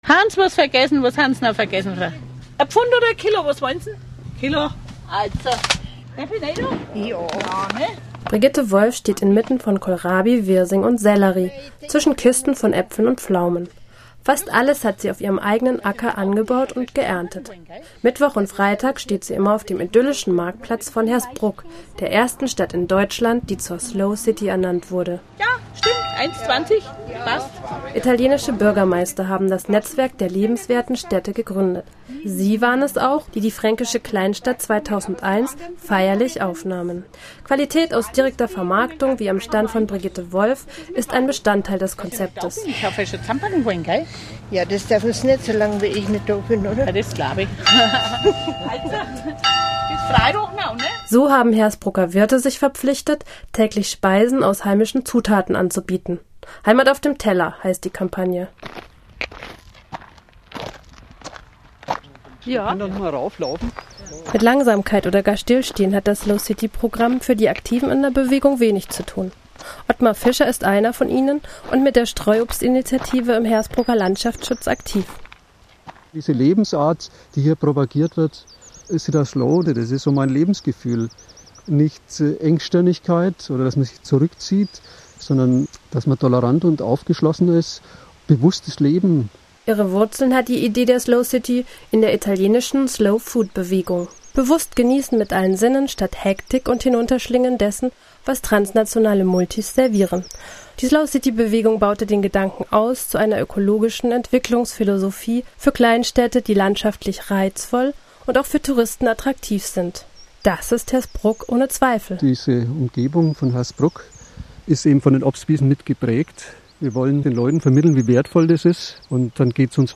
Ein Interview des Deutschlandfunks in der Baugrube, denn man muss sich in der Cittaslow auch irgendwann mal auf seine Arbeit konzentrieren, so nett und interessiert die Journalisten auch sein mögen